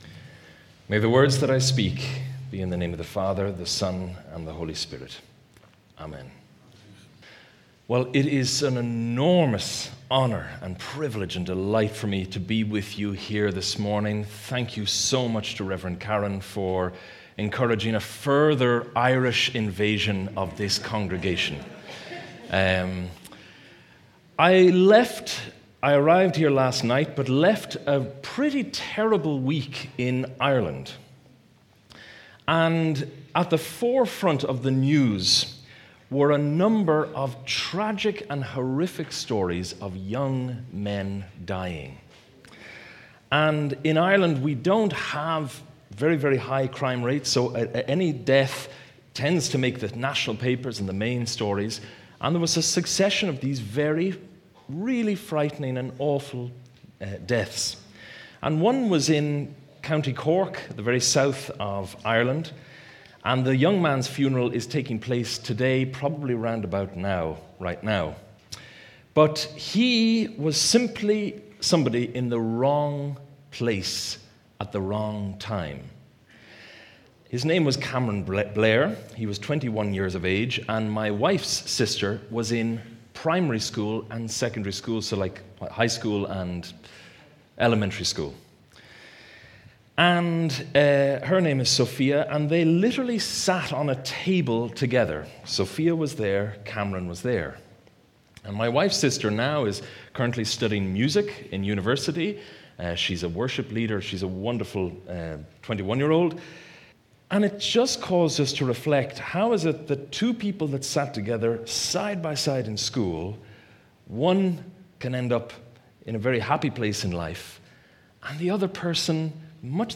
Church of the Servant Sermons